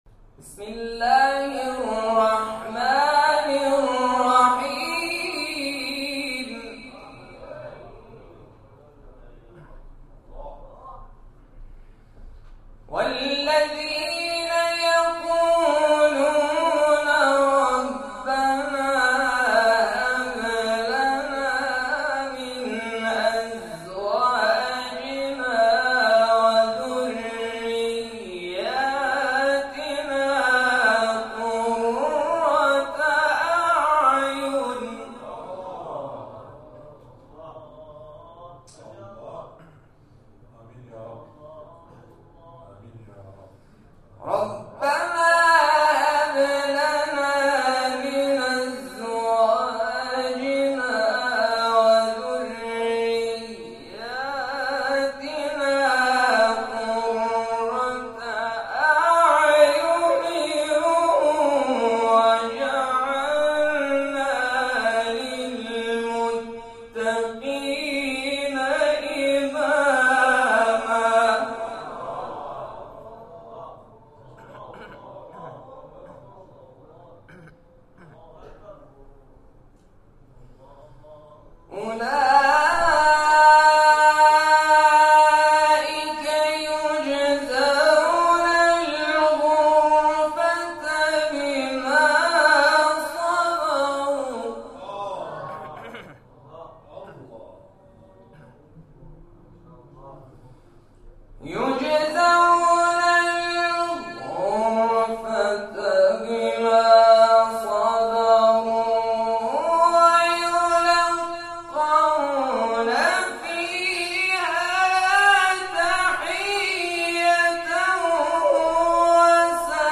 در ادامه تلاوت‌های منتخب ارائه می‌شود.